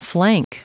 Prononciation du mot flank en anglais (fichier audio)
Prononciation du mot : flank